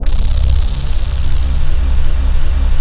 MACHINERY.wav